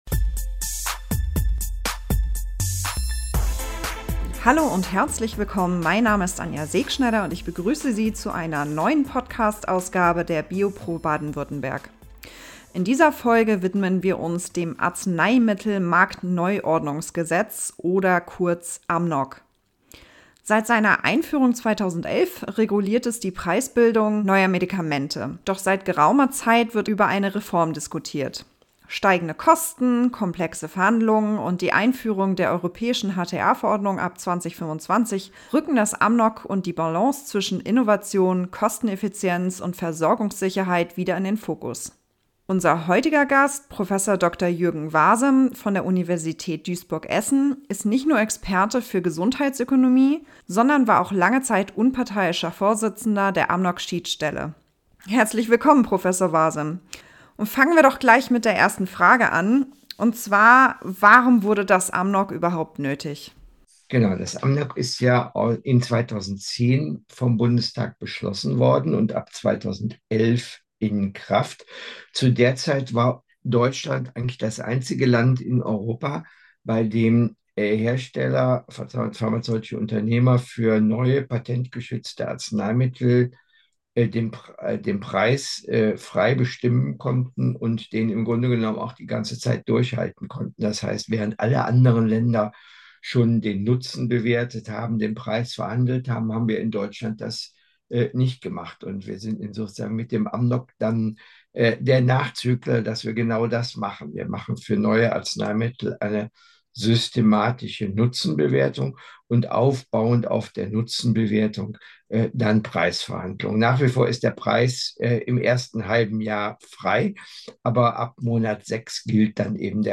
Im Interview spricht er über die Abläufe des Verfahrens und die Preisverhandlungen, vergangene Änderungen, sowie aktuelle und potentielle künftige Herausforderungen mit Blick auf die Balance zwischen Innovation, Kosteneffizienz und Versorgungssicherheit.